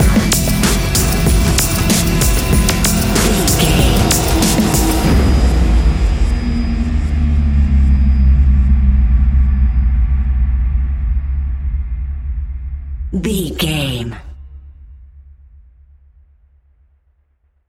Ionian/Major
C♯
industrial
dark ambient
EBM
experimental
synths